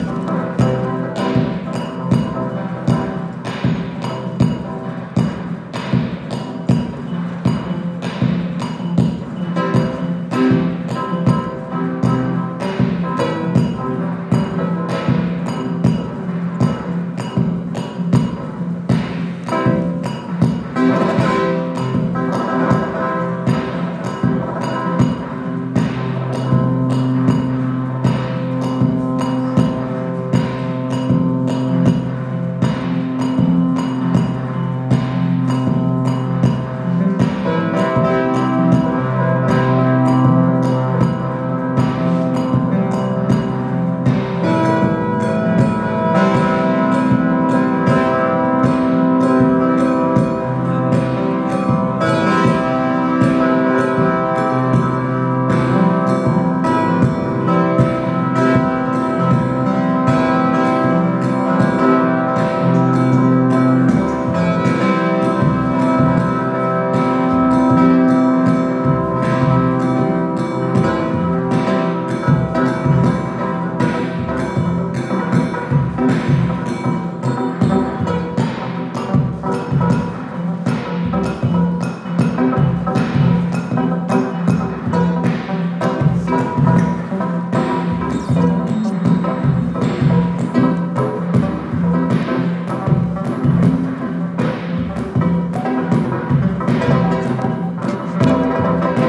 playing live
awesome modular synth patching